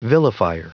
Prononciation du mot vilifier en anglais (fichier audio)